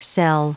Prononciation du mot cel en anglais (fichier audio)
Prononciation du mot : cel